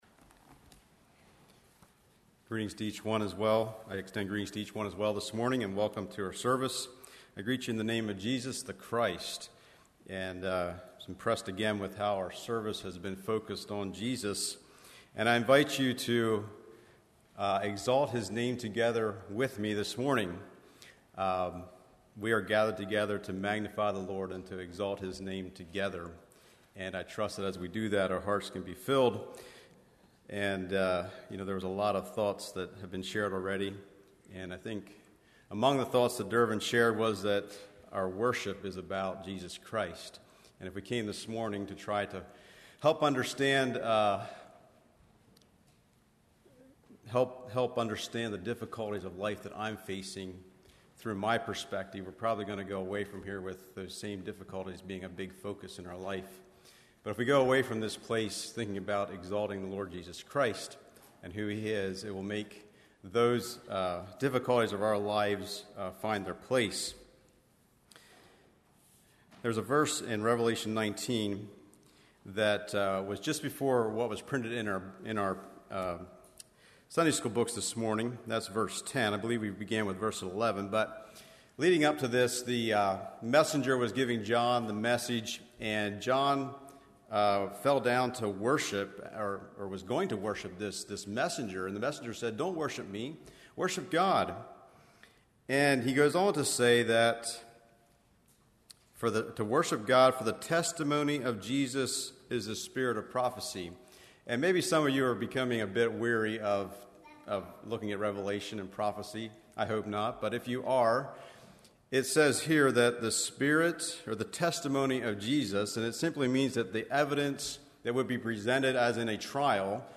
Congregation: Swatara